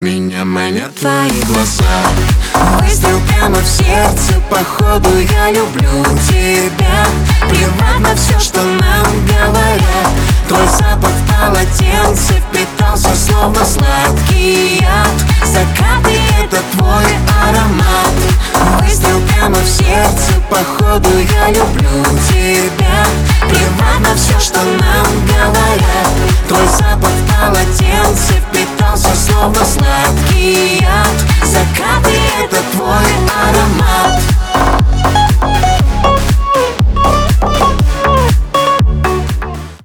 • Качество: 320 kbps, Stereo
Поп Музыка
клубные
громкие